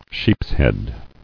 [sheeps·head]